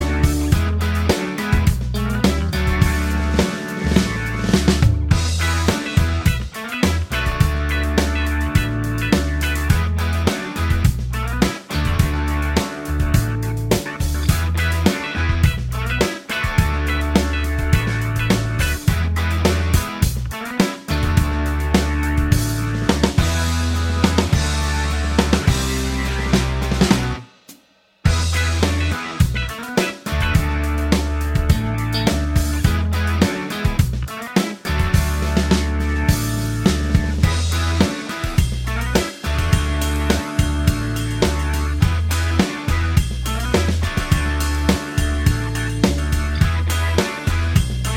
Minus Main Guitar Soft Rock 3:46 Buy £1.50